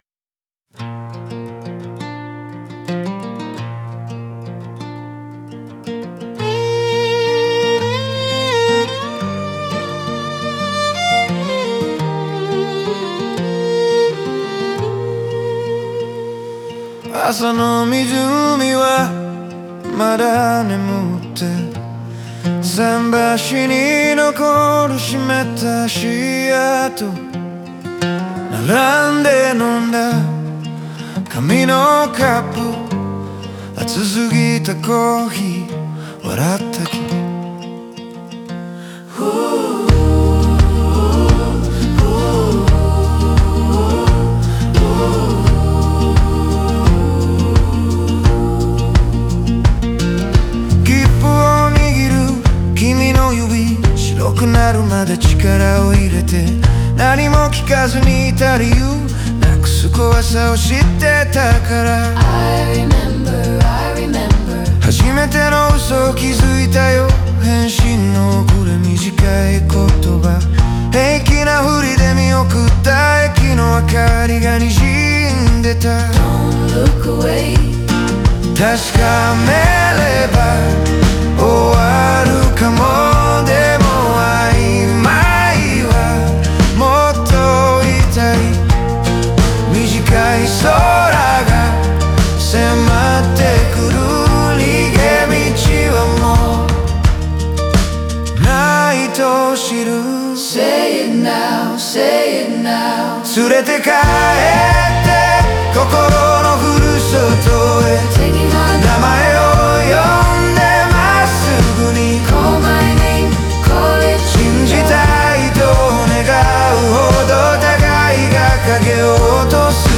感情は抑制されつつも確実に高まり、最後には静かな希望と余韻を残して終わる。